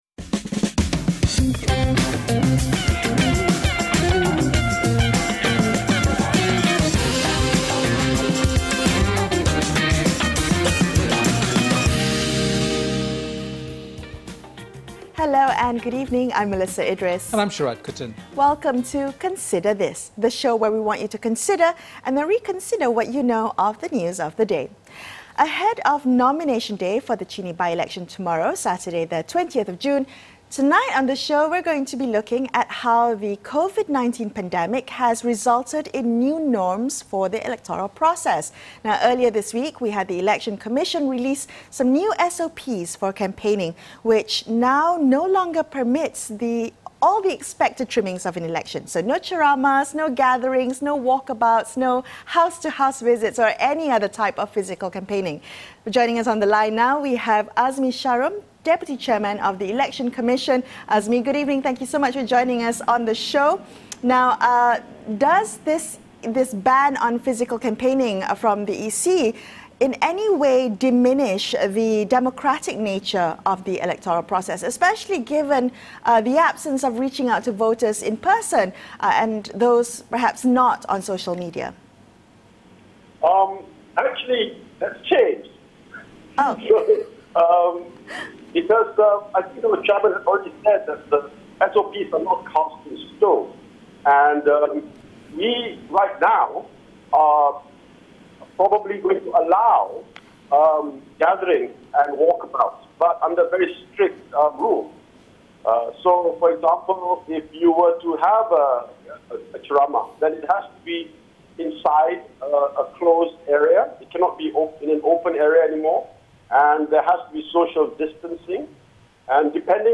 speak to Azmi Sharom, Deputy Chairman of the Election Commission.